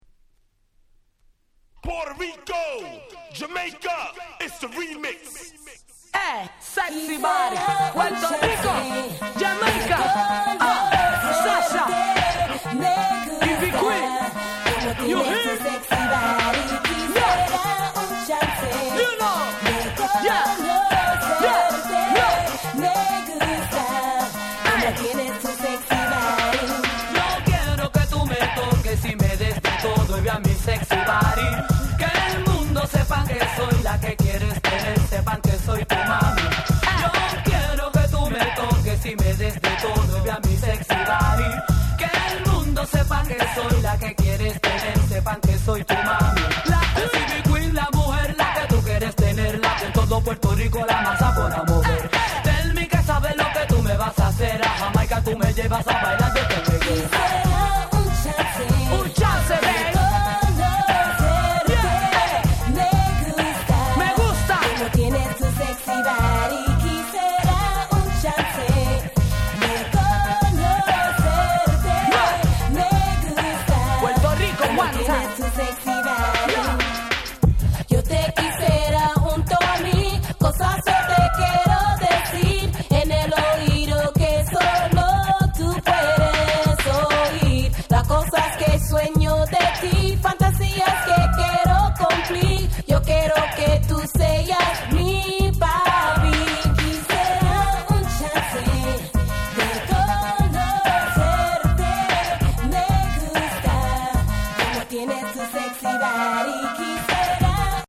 04' Smash Hit Dancehall Reggae/Reggaeton !!
歌もSpanishに歌い直しておりアゲアゲ度3割増しに！！